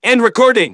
synthetic-wakewords
ovos-tts-plugin-deepponies_Scout_en.wav